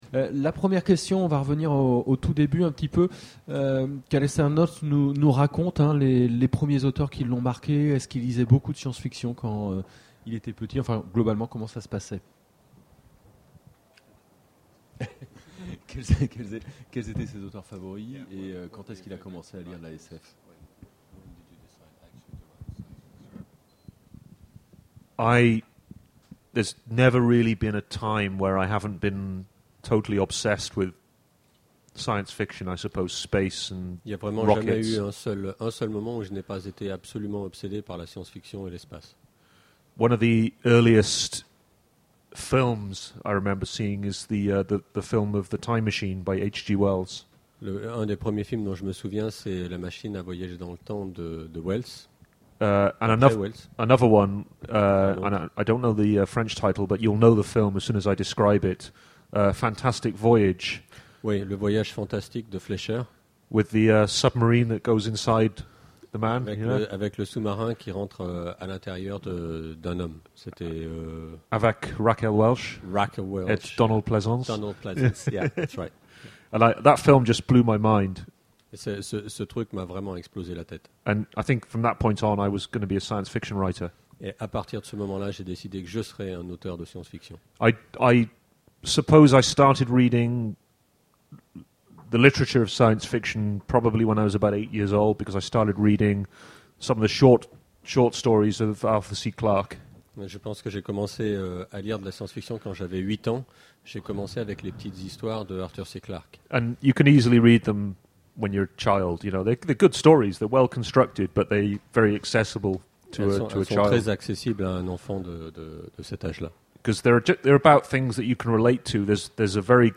Imaginales 2013 : Conférence Entretien avec... Alastair Reynolds
- le 31/10/2017 Partager Commenter Imaginales 2013 : Conférence Entretien avec... Alastair Reynolds Télécharger le MP3 à lire aussi Alastair Reynolds Genres / Mots-clés Rencontre avec un auteur Conférence Partager cet article